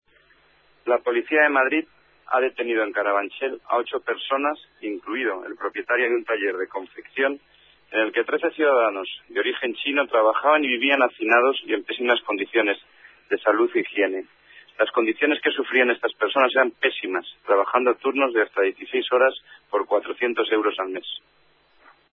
Nueva ventana:Declaraciones de Javier Conde, coordinador general de Seguridad y Emergencias